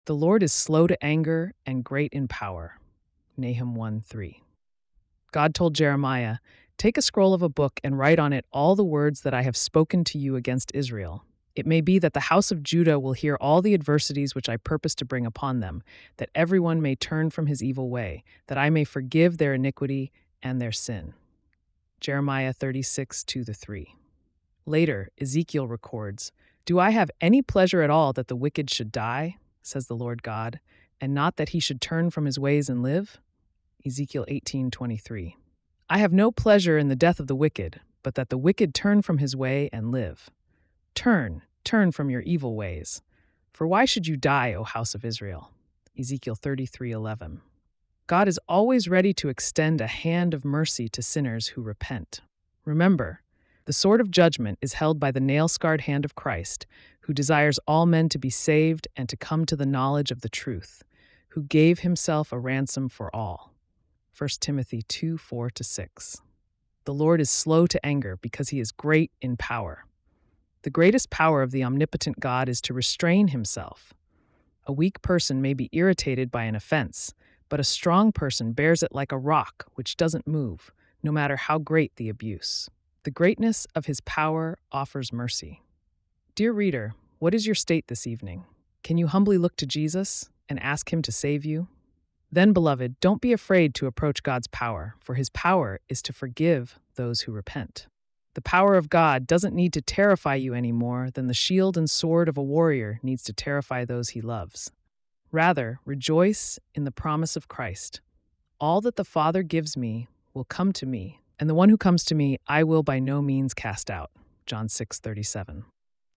February 22 Evening Devotion